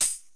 tamboz.wav